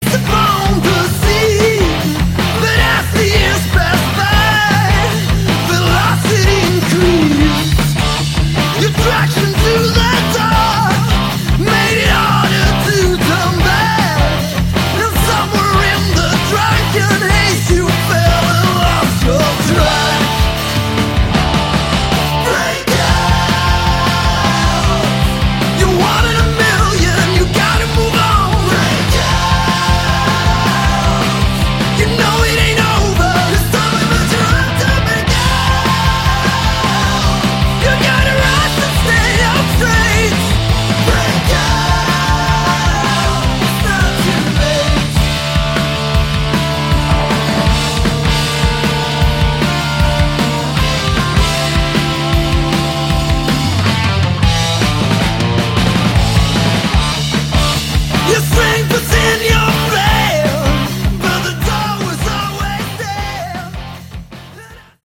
Category: Hard Rock
drums
guitars
vocals, bass